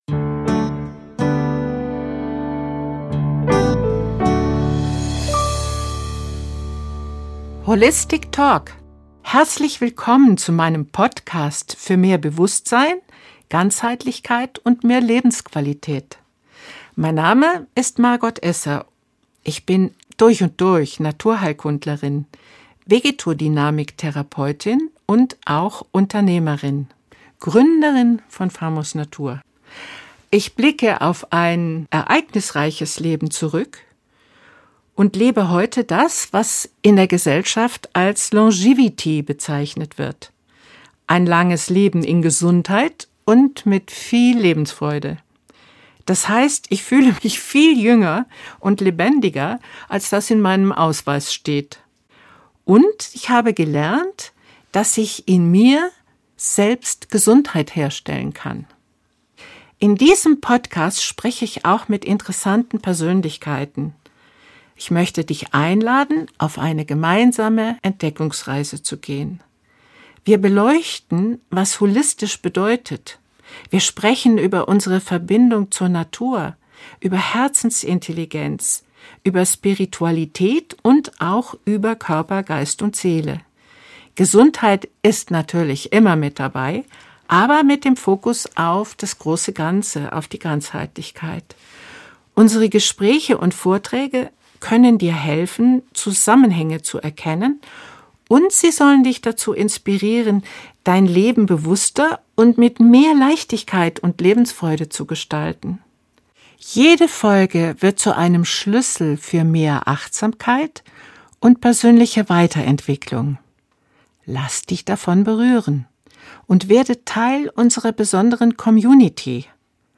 Ein Gespräch über Energie, Bewusstsein und Nachhaltigkeit.